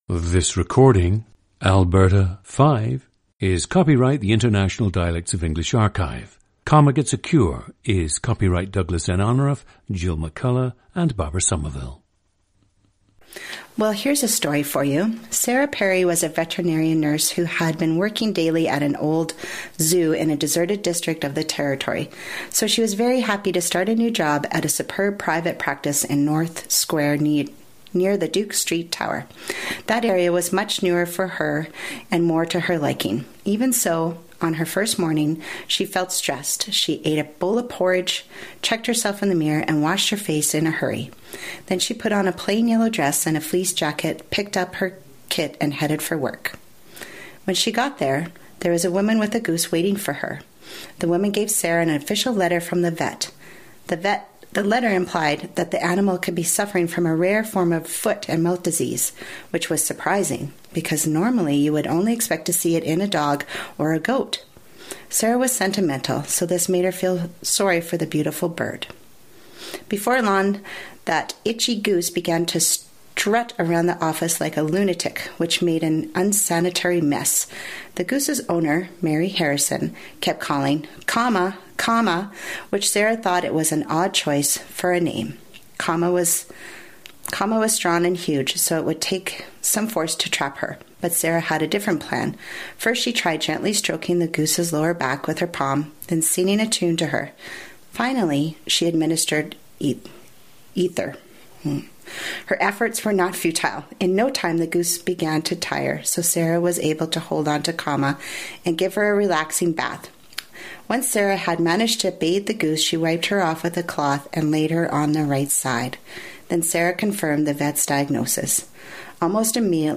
GENDER: female
Specific European influences seem muted, perhaps on account of her living in different small communities around southern Alberta throughout her life.
A raised tongue position results in an increase in greater consonant dentalization and narrower range of vowel sounds noticeable in words such as “community” [kˈmjunəɾi].
[ɪ] pulls down towards [ɘ] or [ə]; examples: “difference” and “influence.”
Syllable truncation occurs on words like “community” and “parents” [ˈpɛɹn̩s].
Nasal vowels, also related to a raised tongue, may be heard in the pronunciation of “and” and “any.”
Terminal rising intonation is especially prevalent when describing or filling in details on a subject; falling intonation indicates the end of the description or response.
A shift of [aʊ] to [ʌʊ] occurs on MOUTH words and greater rounding can be heard on [u] (GOOSE) words.
Medial and final t’s are more present in reading than in free speech but may be heard in “favorite” and “didn’t,” and in “unfortunate” but not “hospital.”